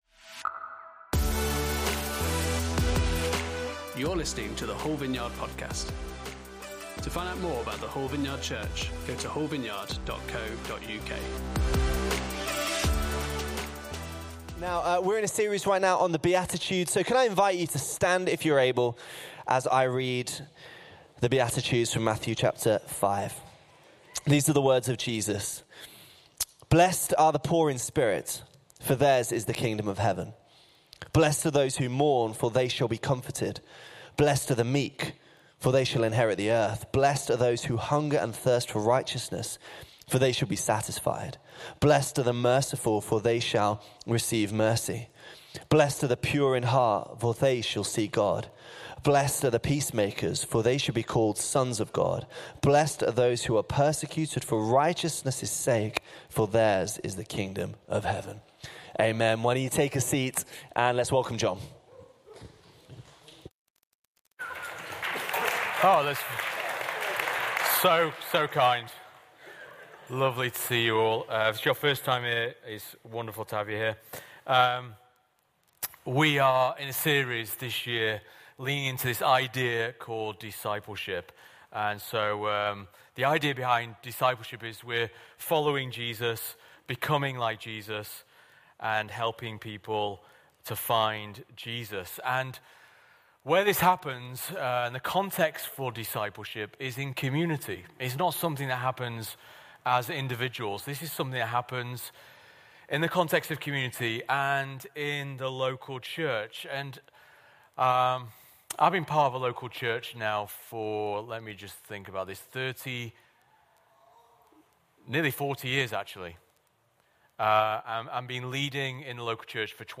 Series: Discipleship: The Beatitudes Service Type: Sunday Service